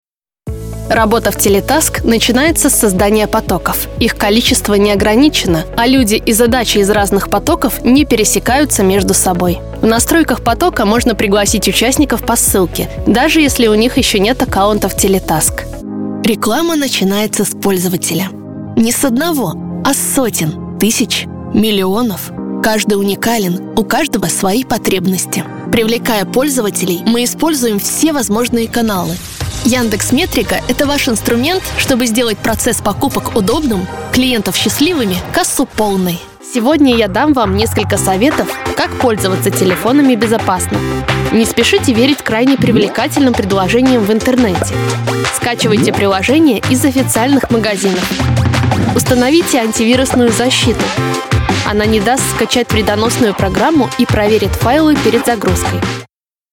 Нежный, жизнерадостный, романтичный, высокий и молодой голос. Особенно специализируюсь на нативной, естественной подаче.
Тракт: Микрофон: Neumann TLM-103 Обработка: Long VoiceMaster Звуковая карта: SSL 2+ Акустическая кабина